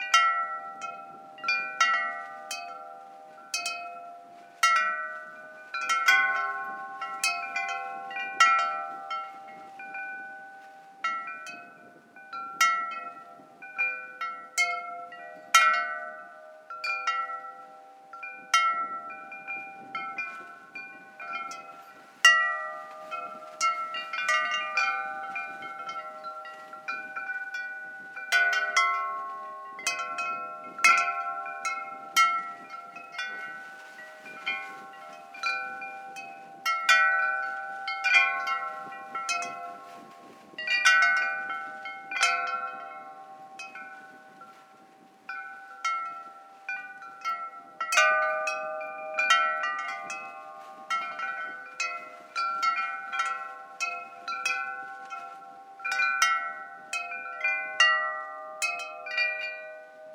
background_wind_chimes_loop.wav